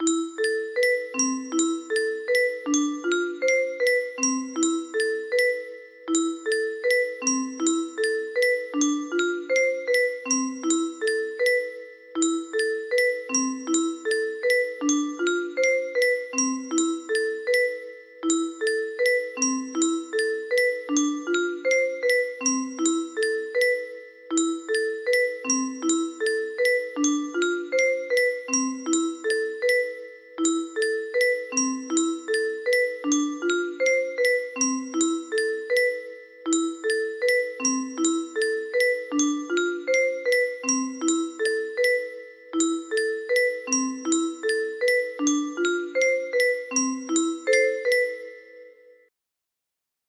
Specially adapted for 20 notes